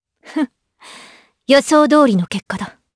Chrisha-Vox_Victory_jp.wav